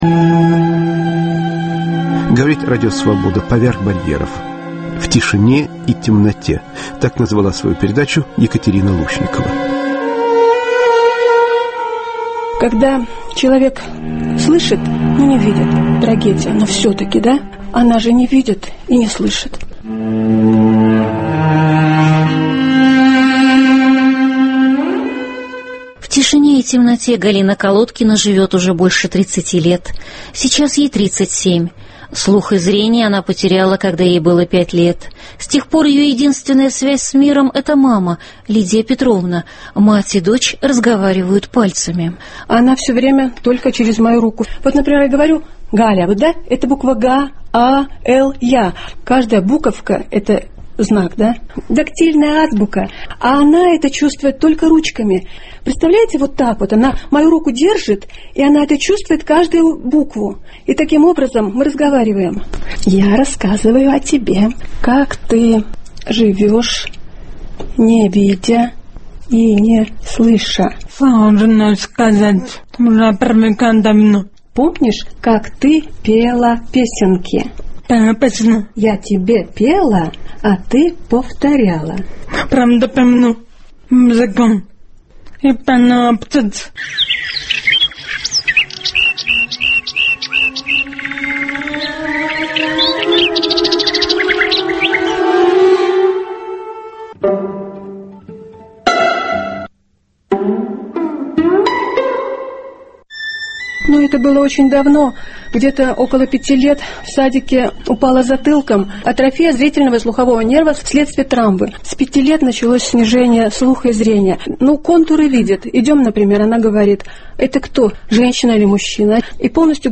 "В тишине и в темноте" В передаче участвуют слепая и глухая девушка и её мать.